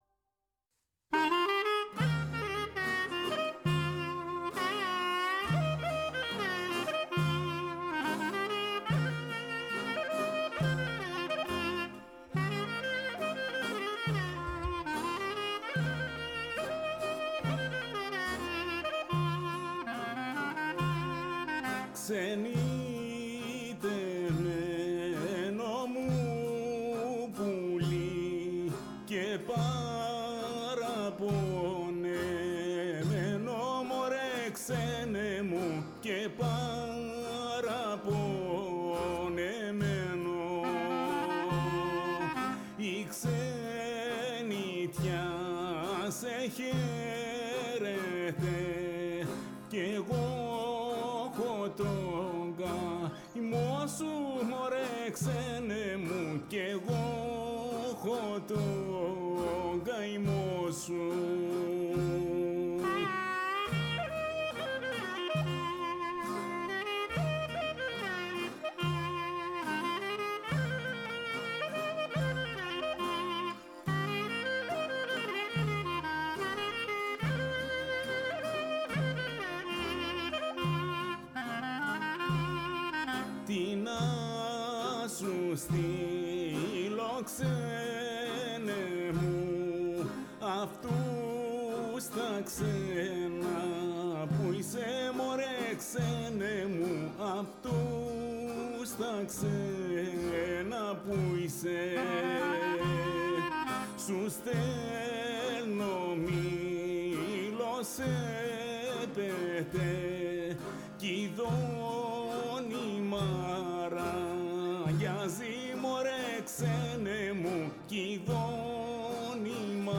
Η ΦΩΝΗ ΤΗΣ ΕΛΛΑΔΑΣ Η Δικη μας Πολη ΜΟΥΣΙΚΗ Μουσική ΣΥΝΕΝΤΕΥΞΕΙΣ Συνεντεύξεις